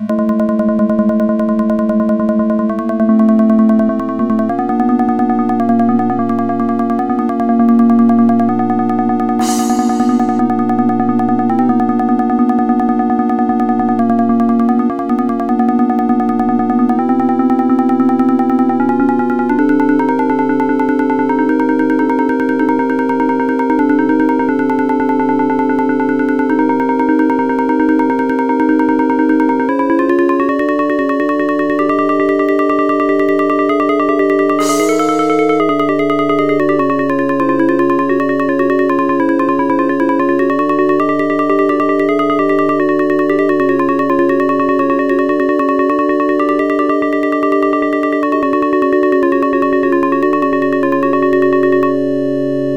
Musical Sonification of Stock Market Data (primarily Google data from 2004-2006)